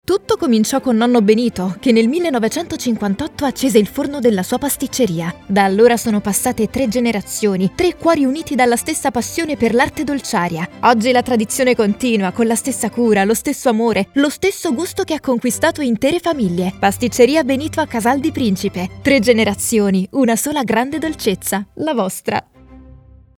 Foreign & British Female Voice Over Artists & Actors
Adult (30-50)